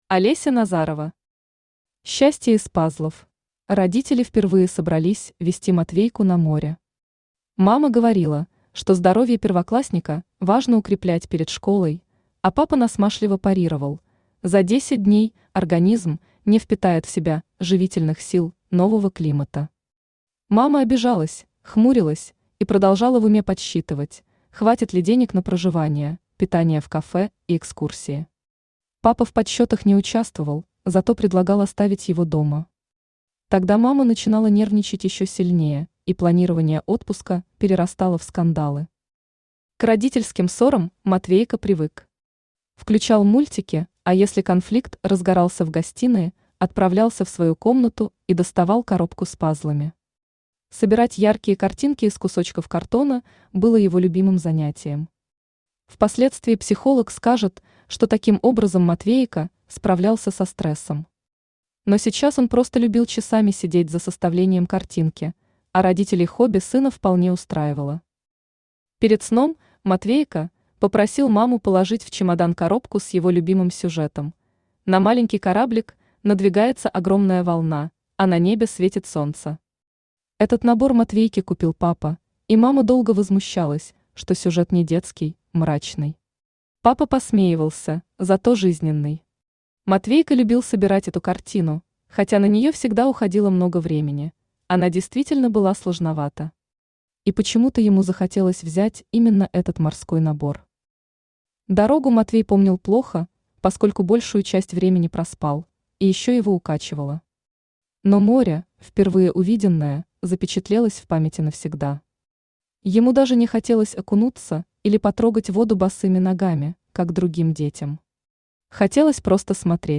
Aудиокнига Счастье из паззлов Автор Олеся Назарова Читает аудиокнигу Авточтец ЛитРес.